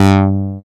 113 CLAV  -R.wav